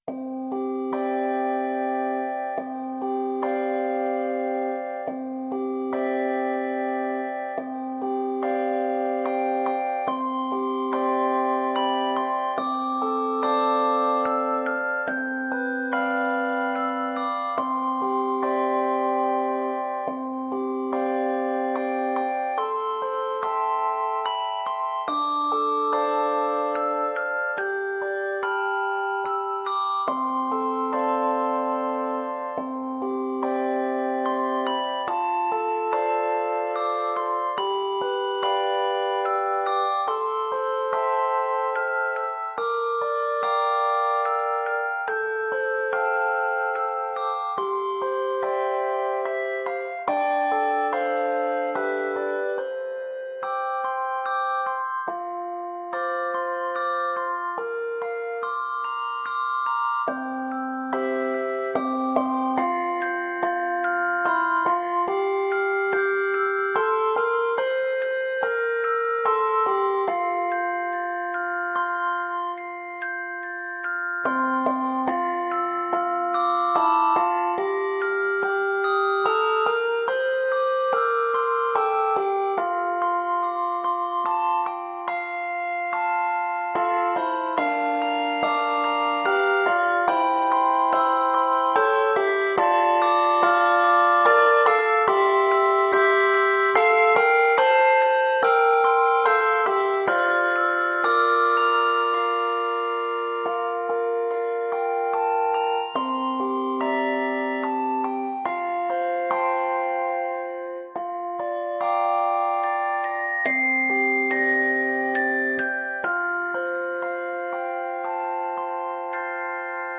Quartet
LV (Let Vibrate)
Handbells